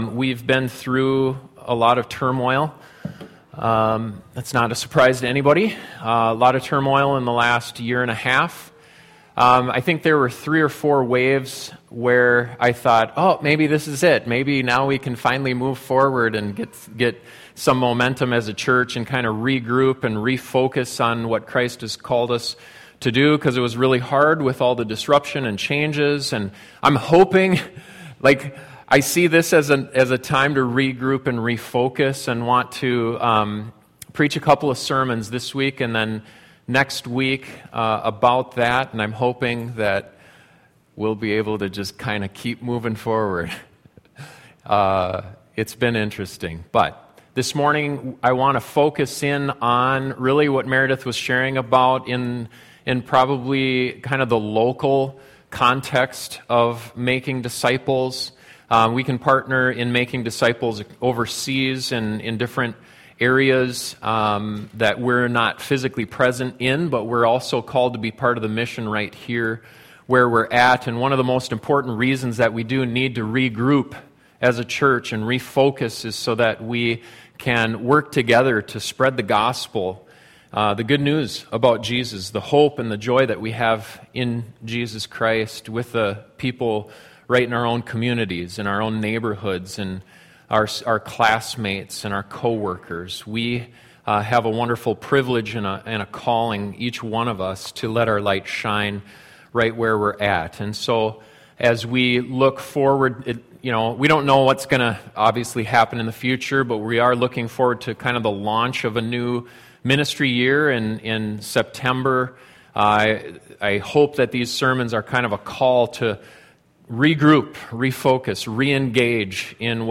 One of the most important priorities for us is to work together to share the gospel in our community. This sermon reminds us why that’s so important.